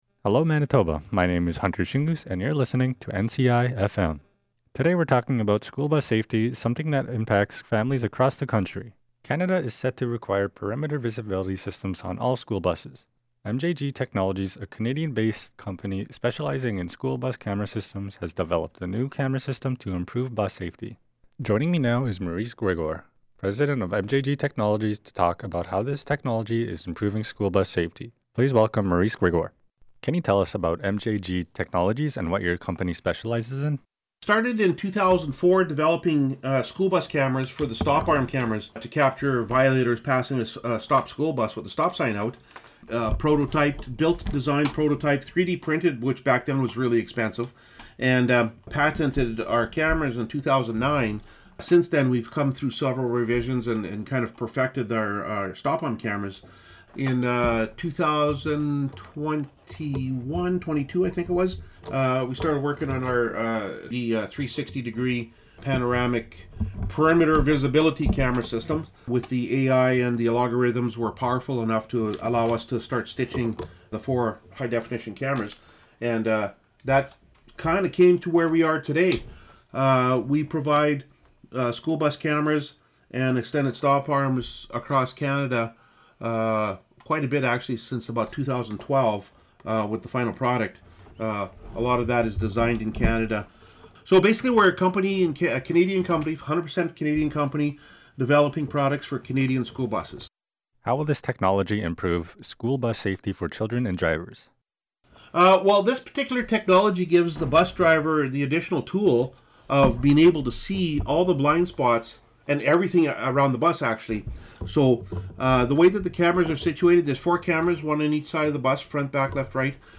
NCI 360 Interview Feb 2025 by NCI
MJG-Tech-Interview-Finished.mp3